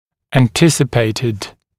[æn’tɪsɪpeɪtɪd][эн’тисипэйтид]предполагаемый, ожидаемый